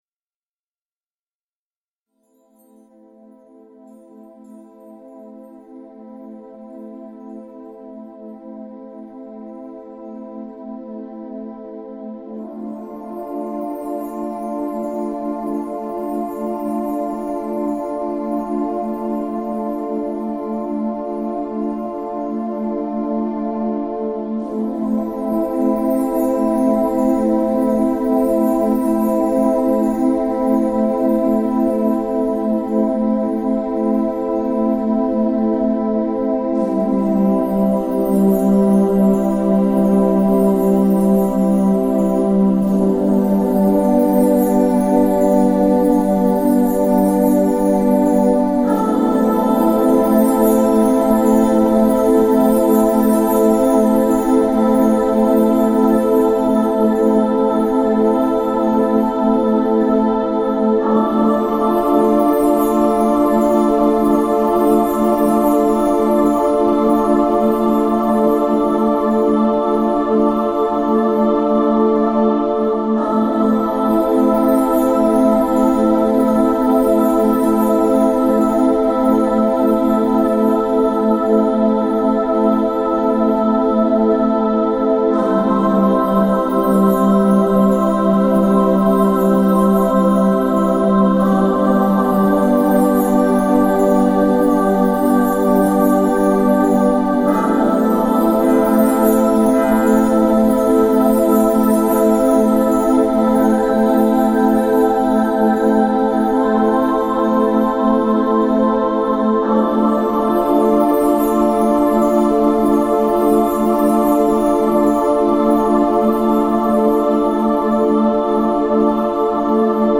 Фоновая музыка для чтения поэзии